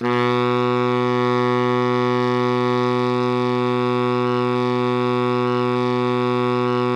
BARI PP B1.wav